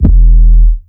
MexikoDro808 (3).wav